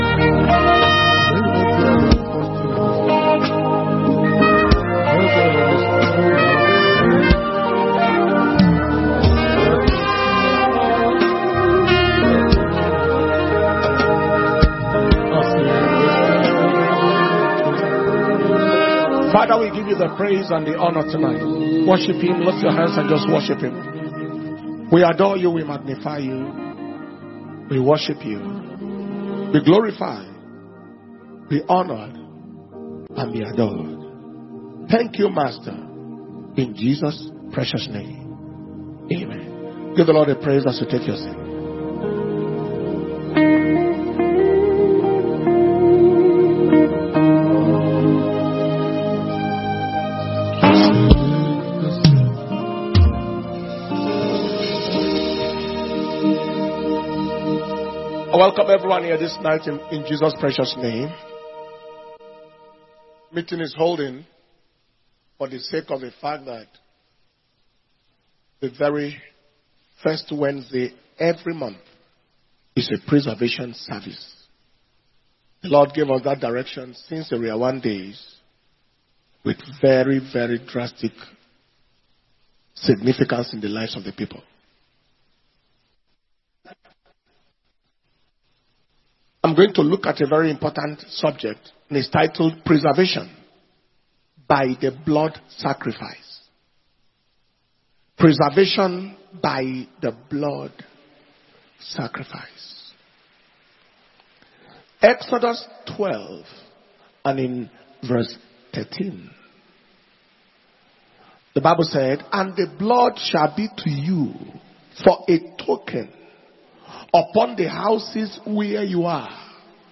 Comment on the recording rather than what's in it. November 2024 Preservation And Power Communion Service